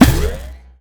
sci-fi_shield_power_deflect_block_01.wav